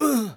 xys受伤3.wav 0:00.00 0:00.38 xys受伤3.wav WAV · 33 KB · 單聲道 (1ch) 下载文件 本站所有音效均采用 CC0 授权 ，可免费用于商业与个人项目，无需署名。
人声采集素材